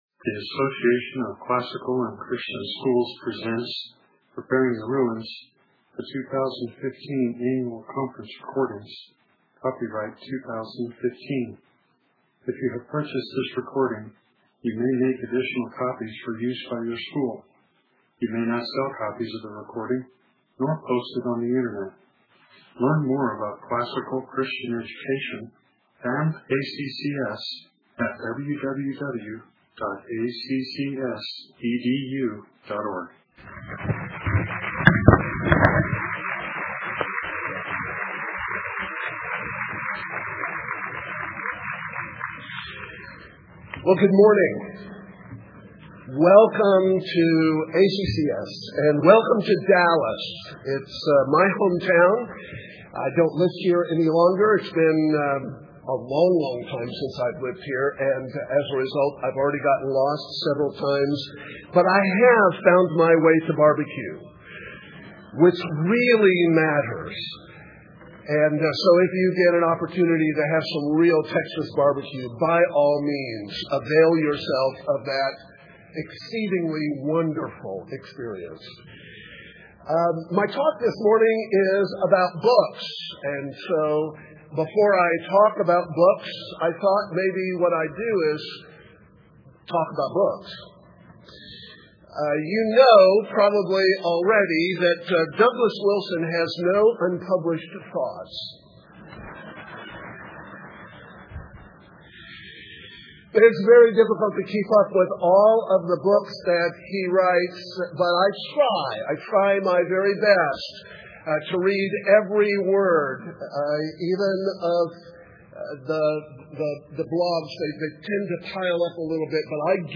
2015 Workshop Talk | 0:43:07 | All Grade Levels, History, Literature
Jan 11, 2019 | All Grade Levels, Conference Talks, History, Library, Literature, Media_Audio, Workshop Talk | 0 comments